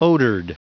Prononciation du mot odored en anglais (fichier audio)
Prononciation du mot : odored